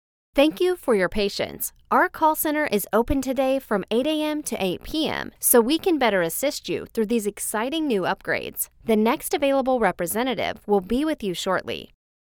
A believable, versatile, mature voice that brings copy to life: the mom or neighbor next door, the business professional, doctor or nurse, sultry siren or sophisticated lady, announcer or storyteller...classy t...